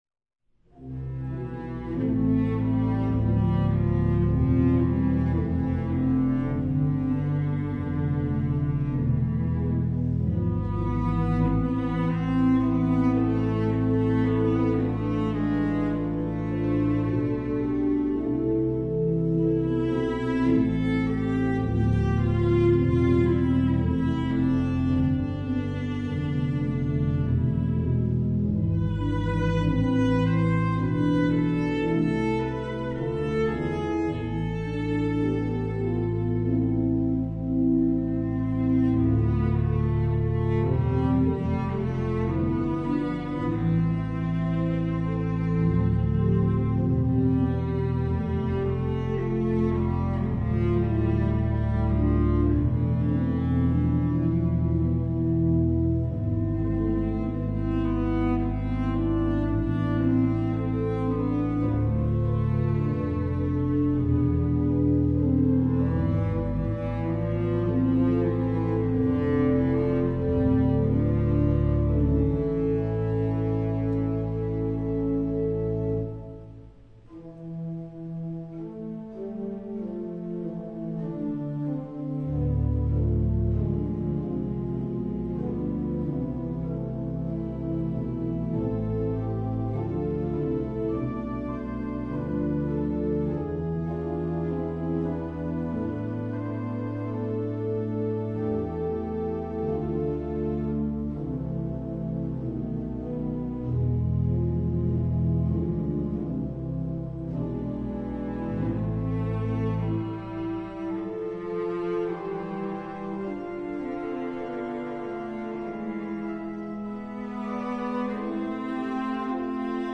Voicing: Cello W/or